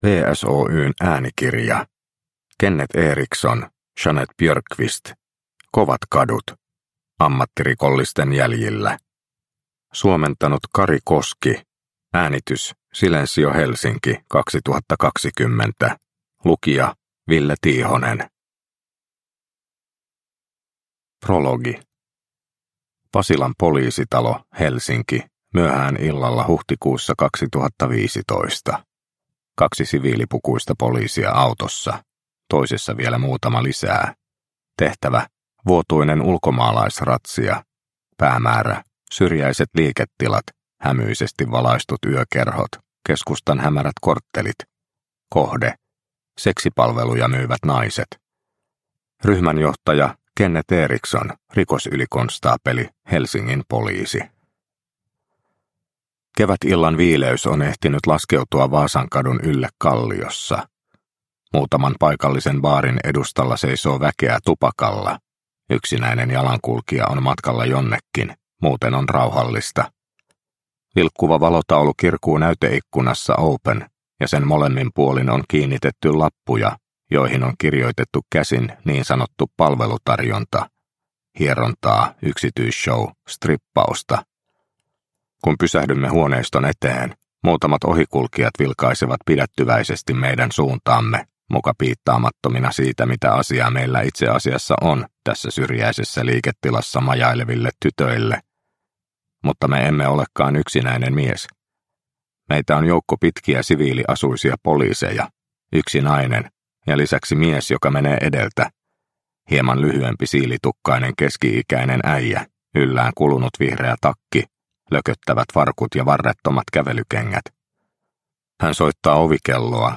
Kovat kadut – Ljudbok – Laddas ner